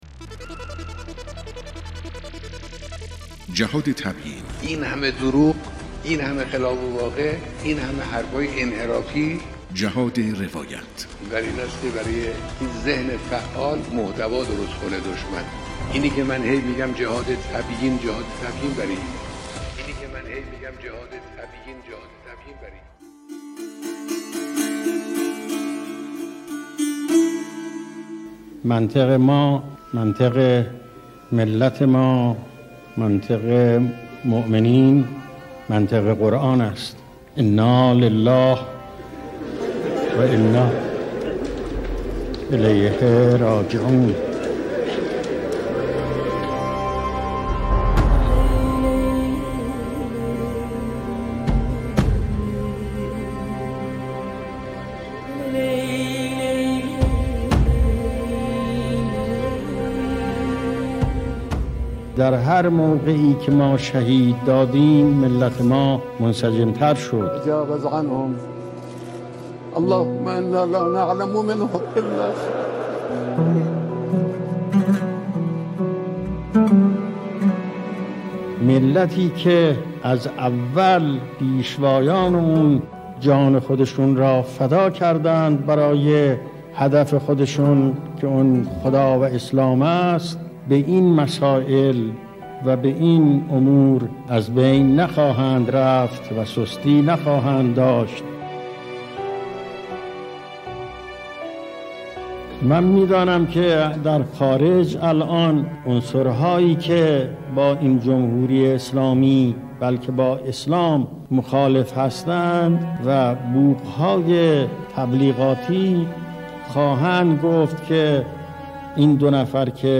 رادیو صدای انقلاب 1679 | بیانات حکیمانه امام خمینی(ره)